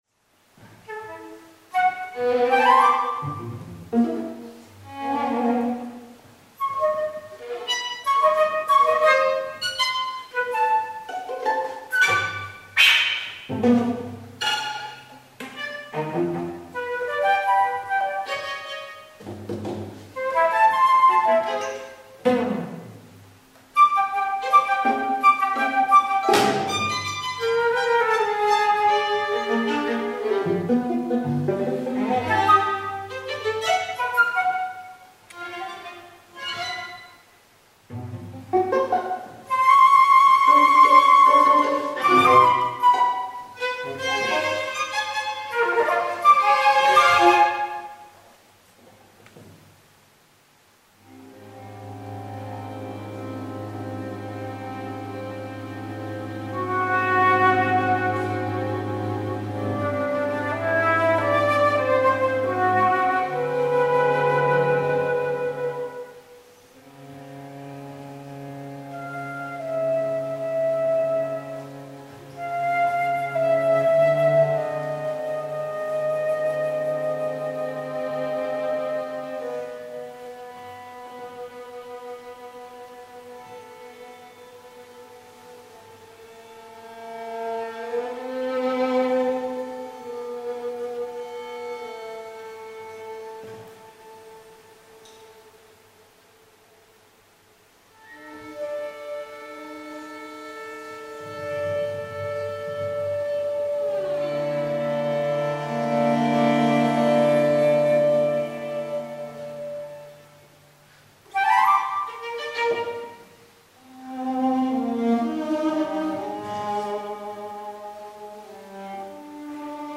Serenade für Flôte und Streichtrio